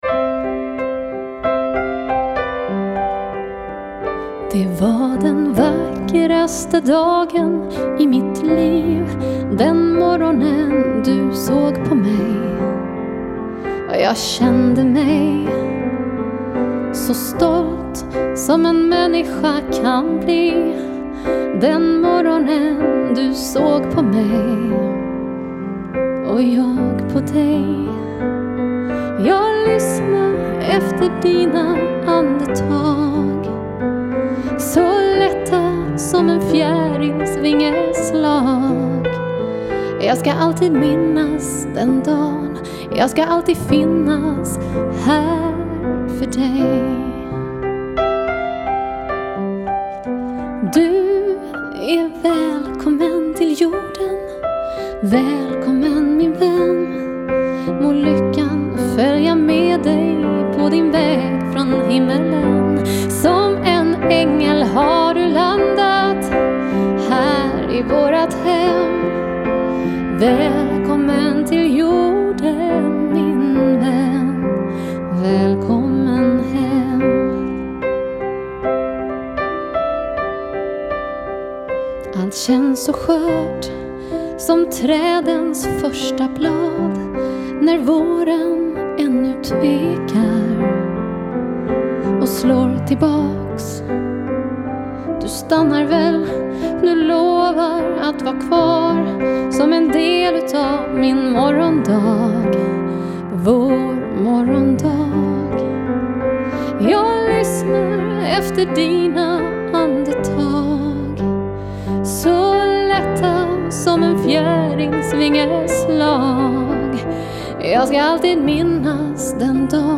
Sång
Piano